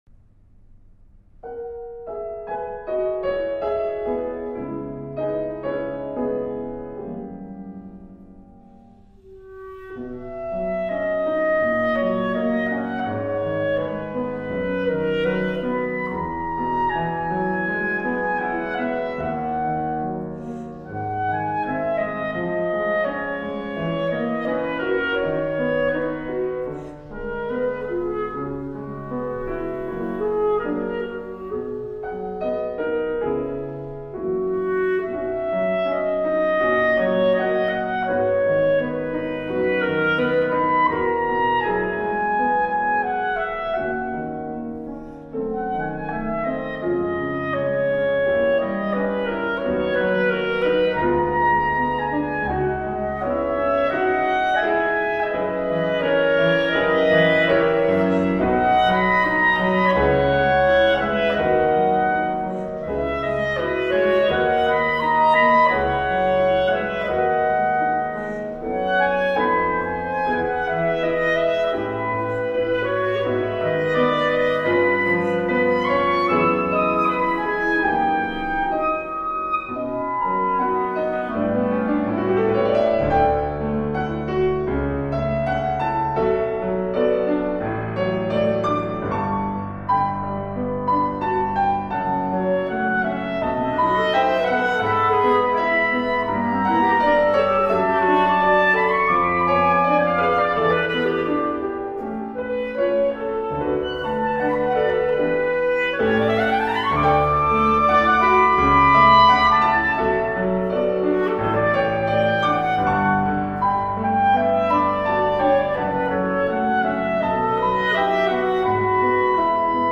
编制：Cl / Cl / Pno
B♭ Clarinet 1
B♭ Clarinet 2
Piano